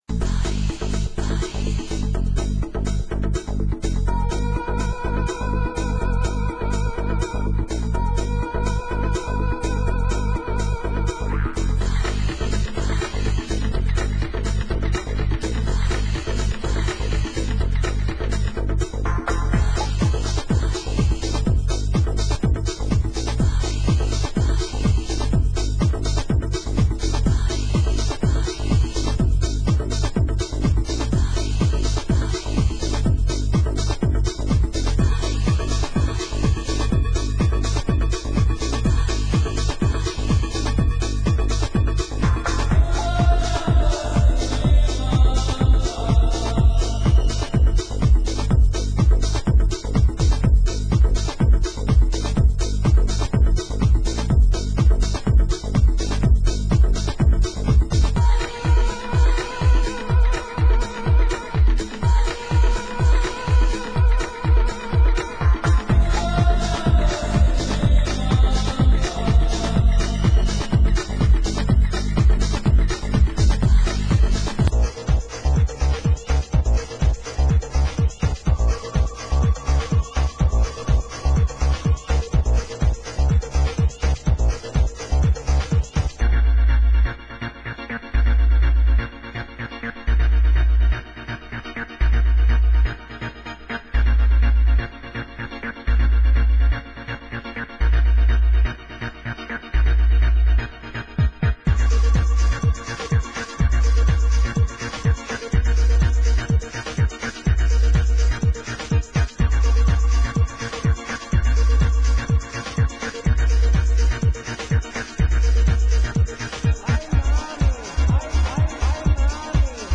Genre: UK Techno